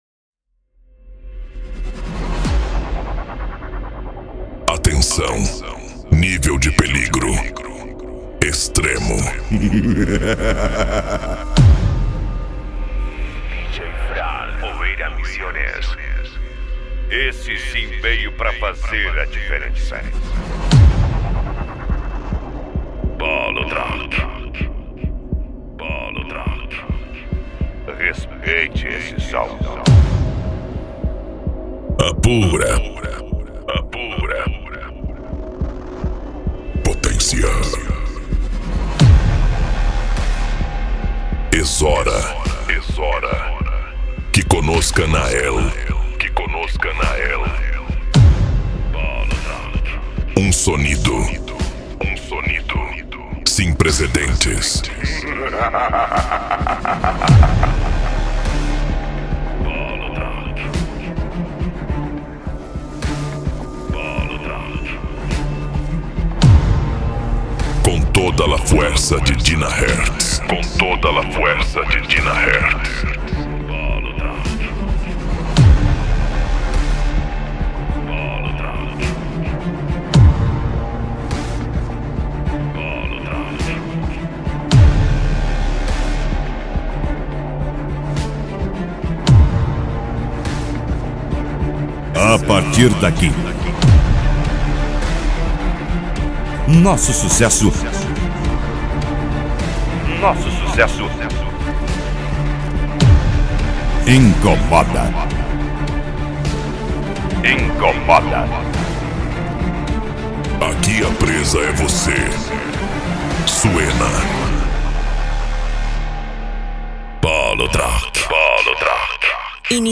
Electro House
Eletronica
Remix